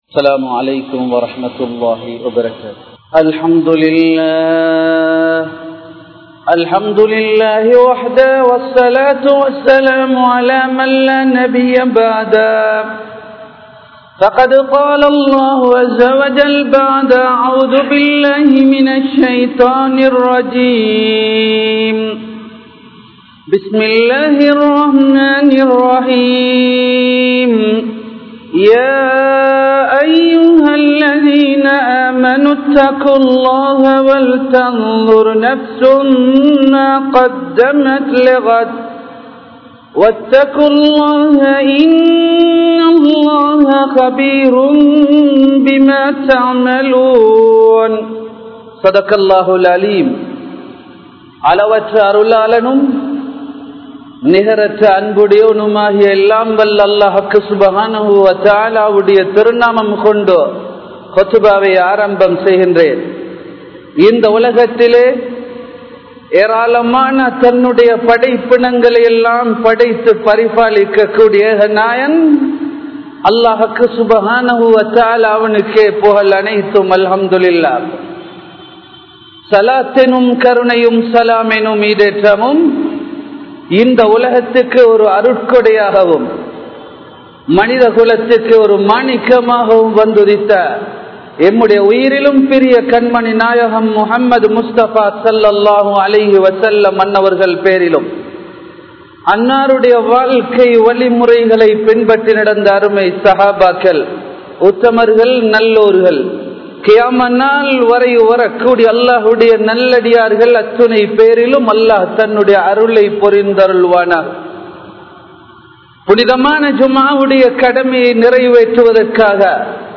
Seeralium Indraya Vaalifarhal (சீரழியும் இன்றைய வாலிபர்கள்) | Audio Bayans | All Ceylon Muslim Youth Community | Addalaichenai